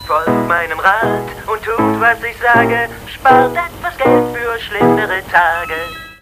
Diverse Radio- und Lautsprecherstimmen (alles Neusynchros)
- Spardose        (Donald's Happy Birthday) -